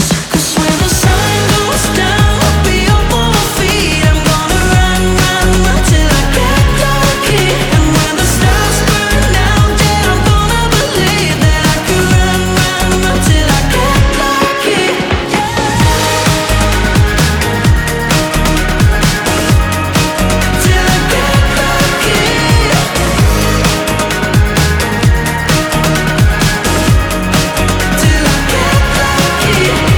2025-06-13 Жанр: Поп музыка Длительность